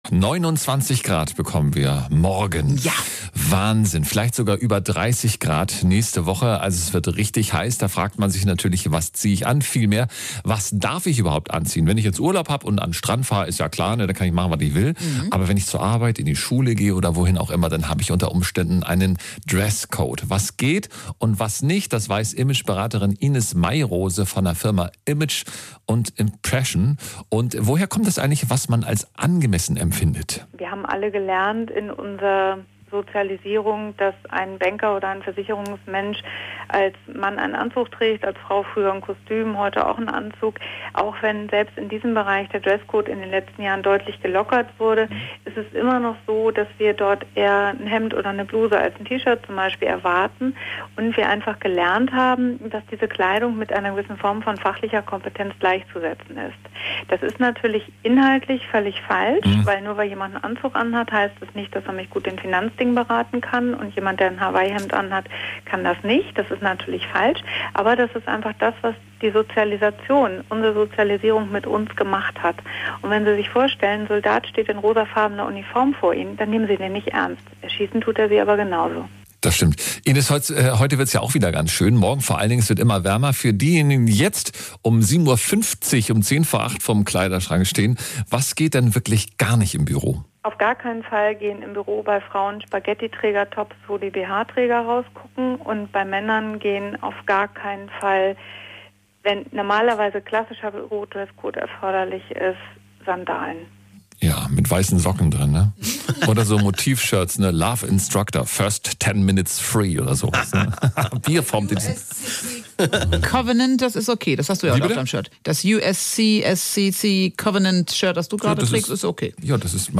Im Interview in der Morning-Show